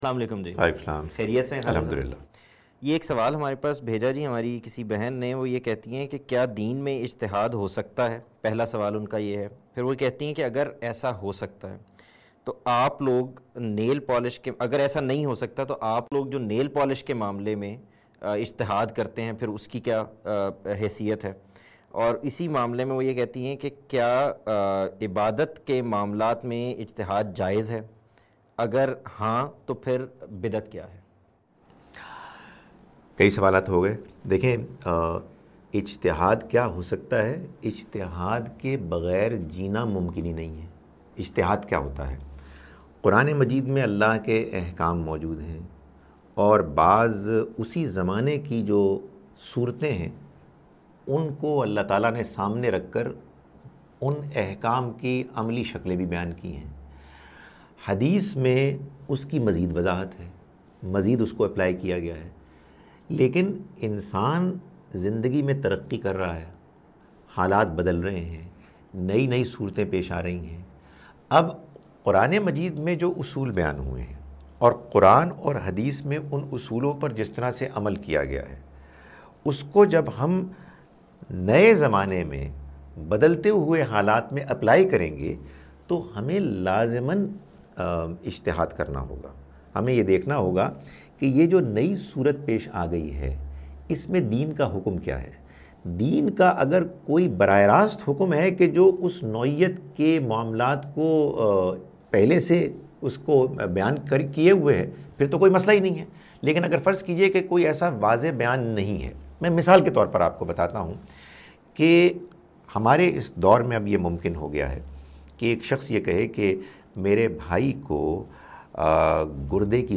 Program "Ask A Question" where people ask questions and different scholars answer their questions